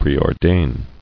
[pre·or·dain]